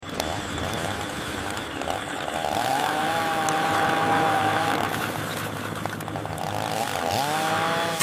Carrot Mining ASMR | AI sound effects free download